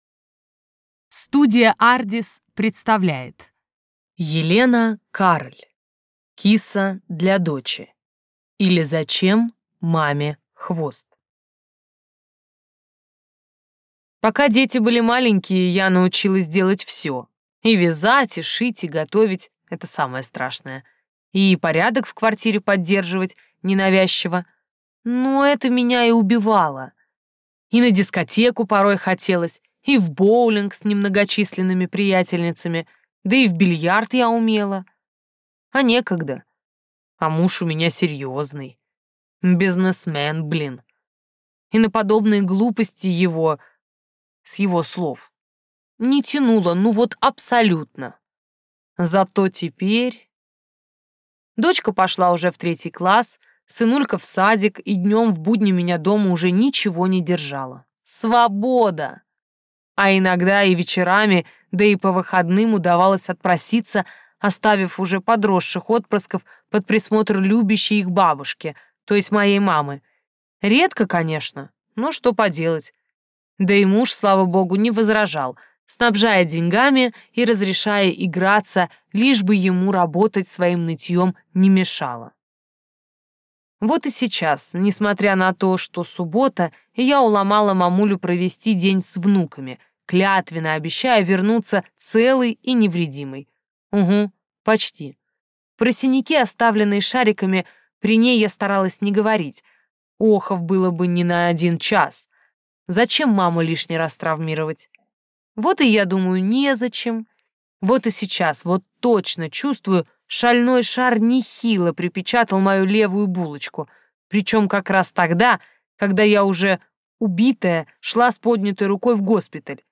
Аудиокнига Киса для дочи, или Зачем маме хвост?